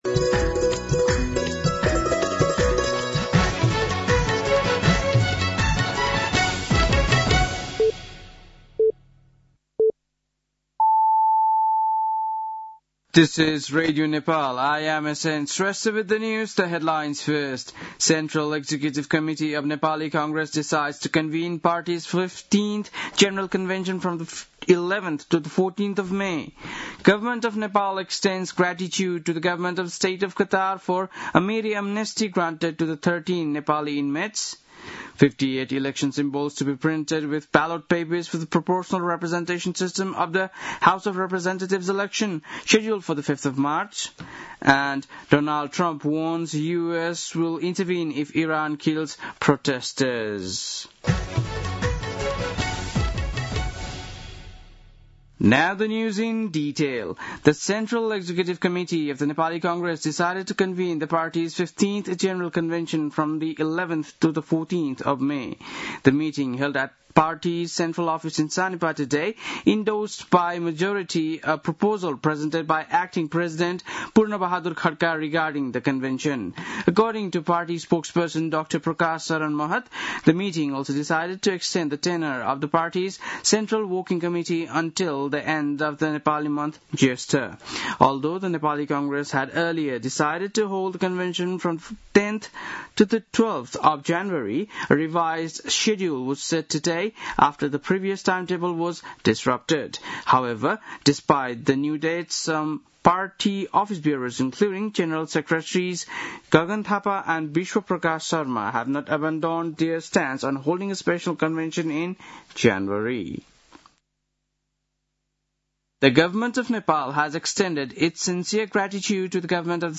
बेलुकी ८ बजेको अङ्ग्रेजी समाचार : १८ पुष , २०८२